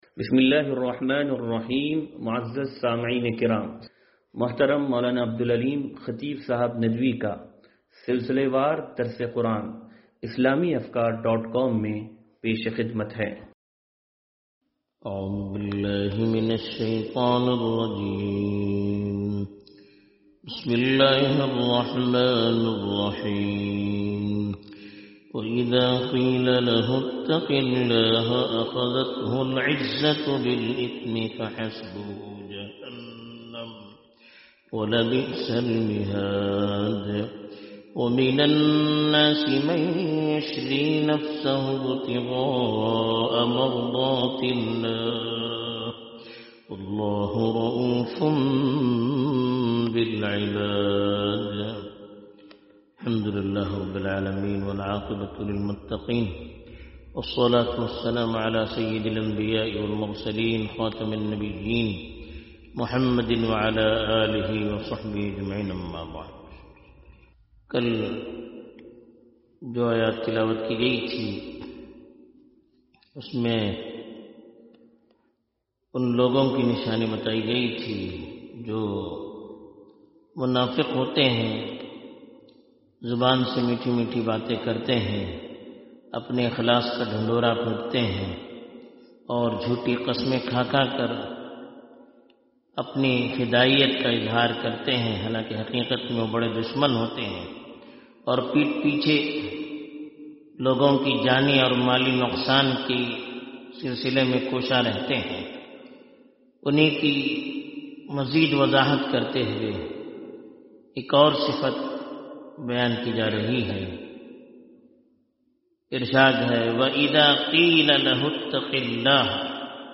درس قرآن نمبر 0152